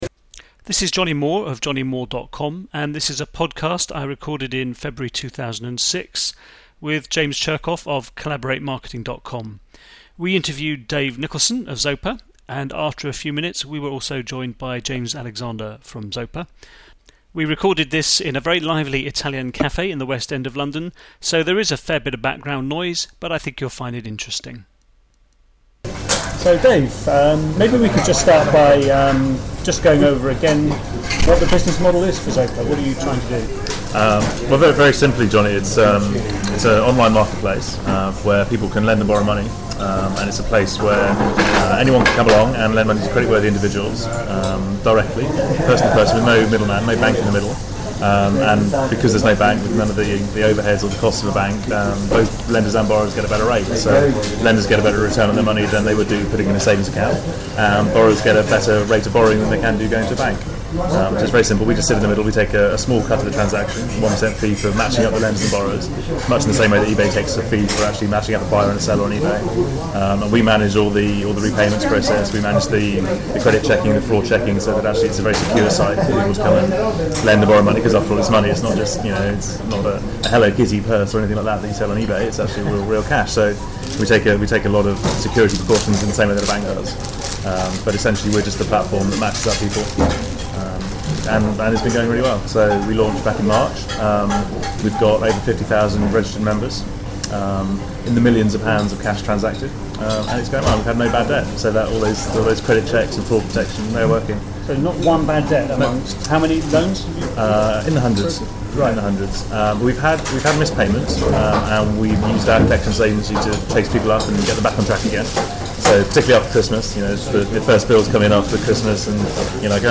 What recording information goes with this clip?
We spoke to them in a noisy Italian cafe in London so you’ll hear a fair bit of background noise – but it’s a very interesting discussion.